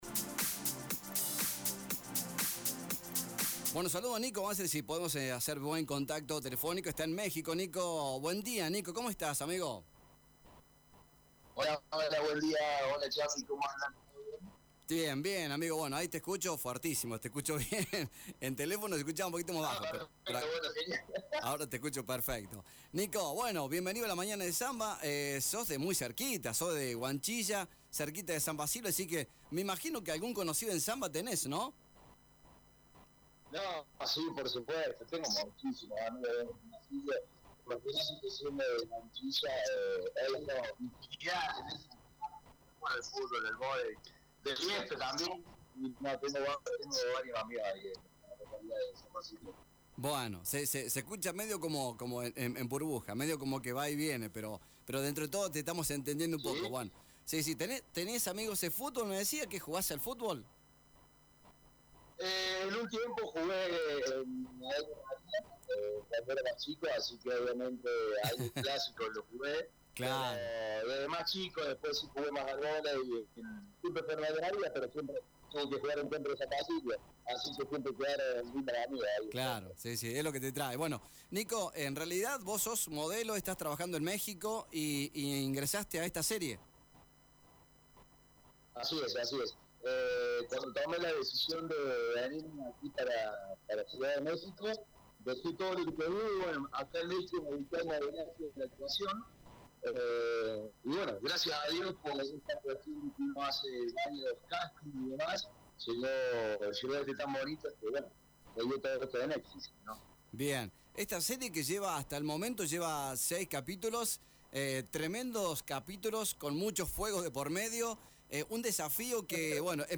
El modelo dialogó con FM Samba.